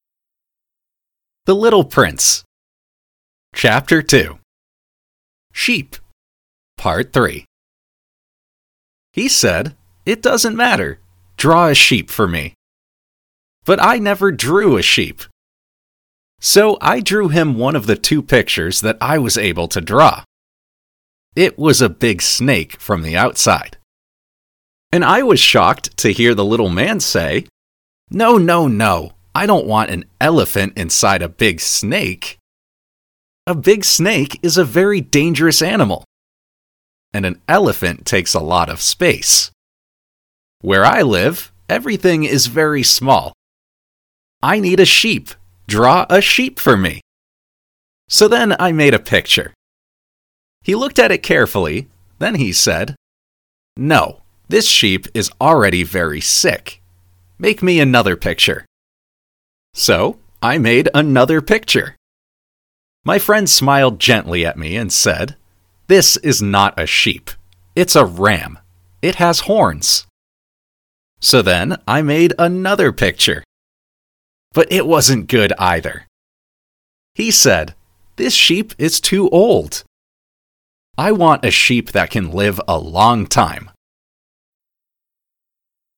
Lesson 26 - Shadowing